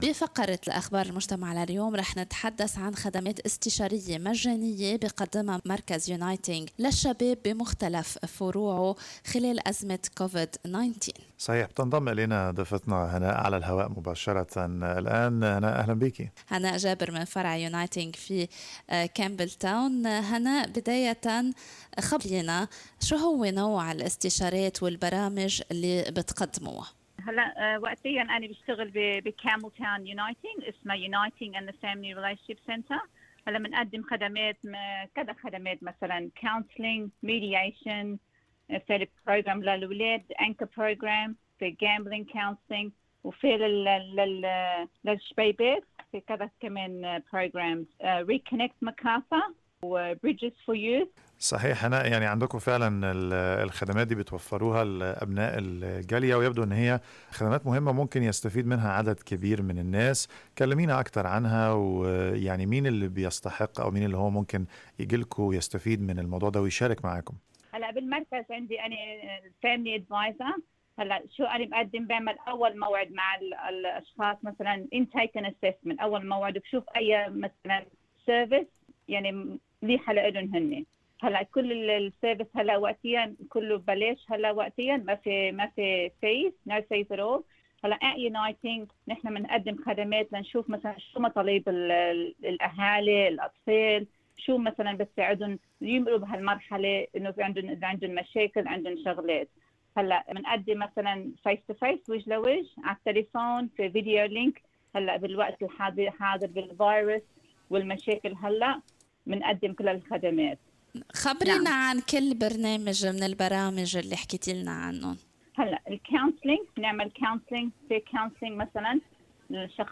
تعرفوا على أبرز الخدمات التي يمكنكم الاستفادة منها من خلال الاستماع إلى اللقاء أعلاه.